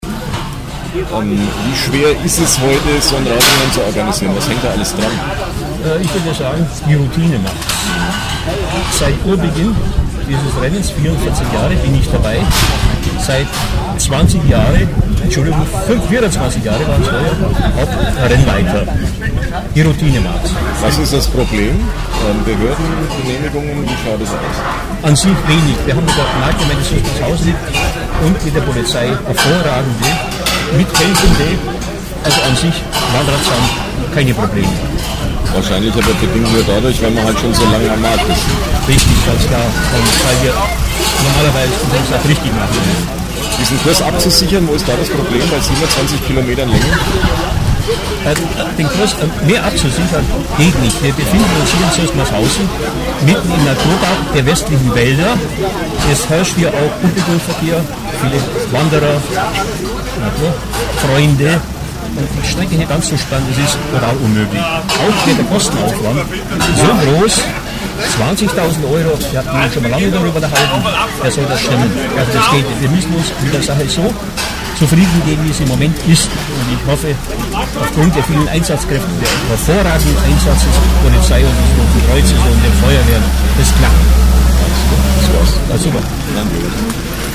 Interviews zum Rennen